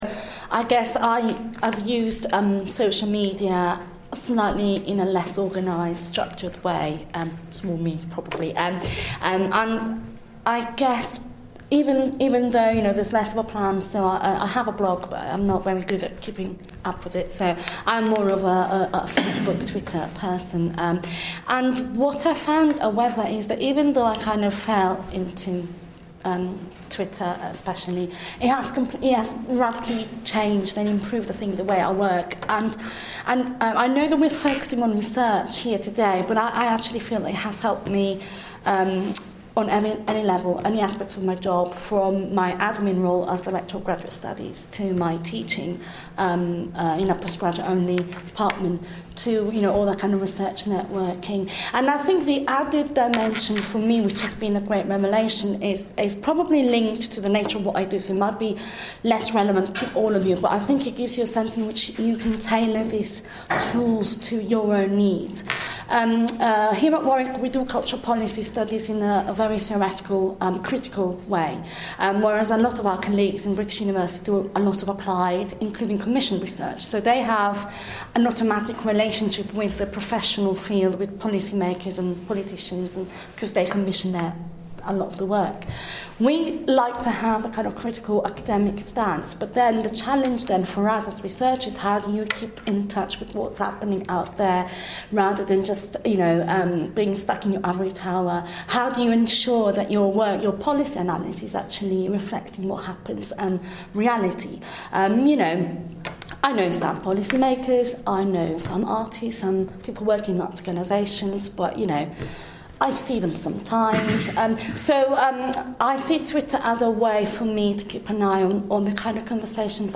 at a Digital Change GPP event earlier this year.